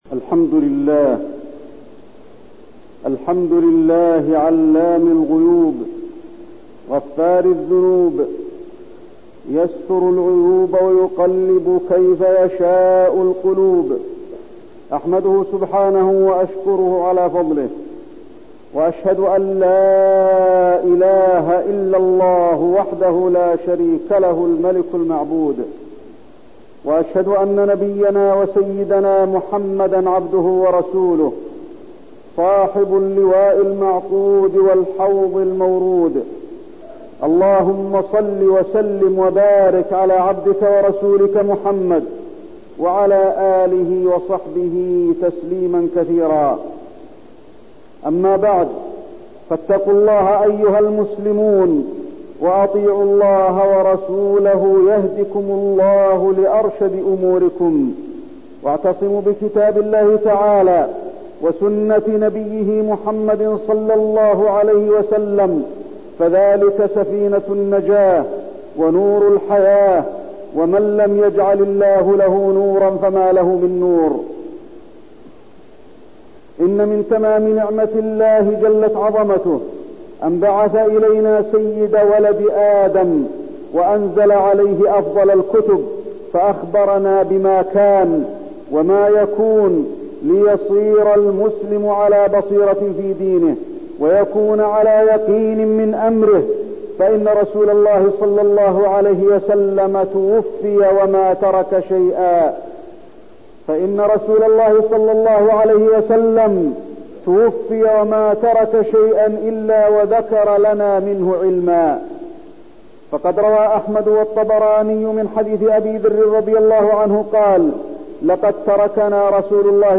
تاريخ النشر ٢٥ صفر ١٤١١ هـ المكان: المسجد النبوي الشيخ: فضيلة الشيخ د. علي بن عبدالرحمن الحذيفي فضيلة الشيخ د. علي بن عبدالرحمن الحذيفي التحذير من الفتن